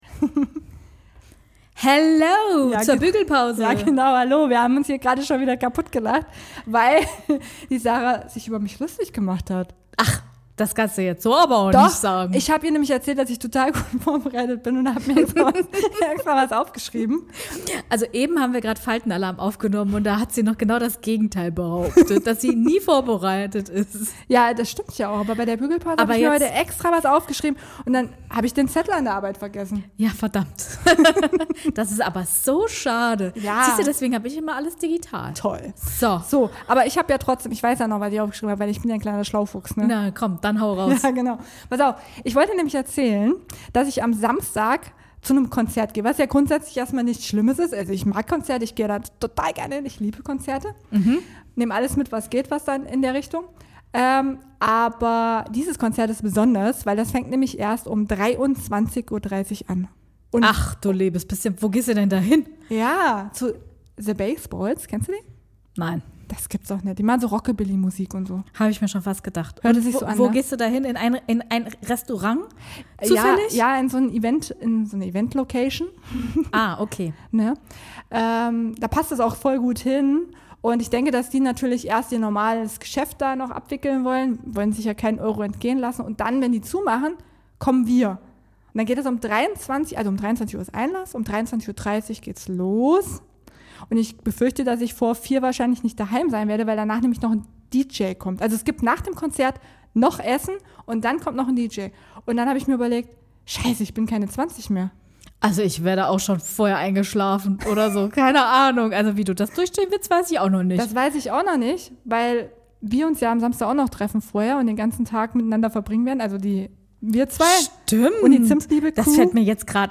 Zwischen Dampfbügeleisen und Bassboxen: Ein Smalltalk über das Älterwerden.
Ein kurzer Realitätscheck zwischen zwei Dampfstößen.